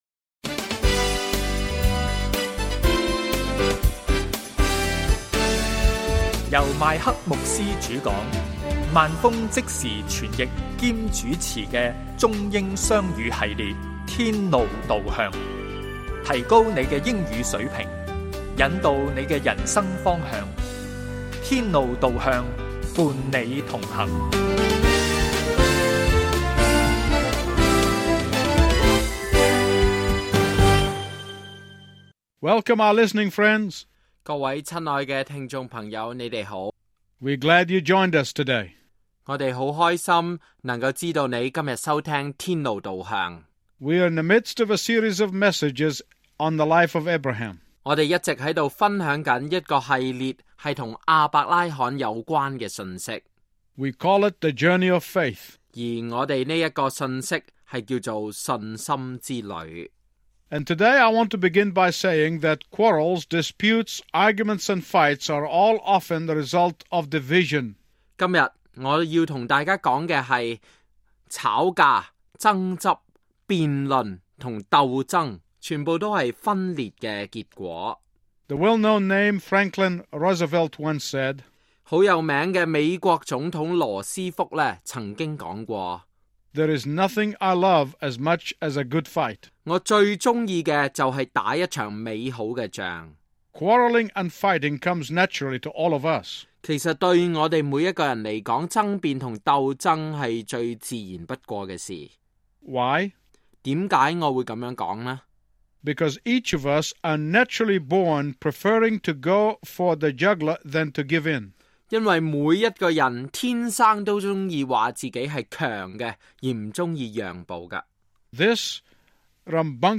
粤语、英语主题式讲座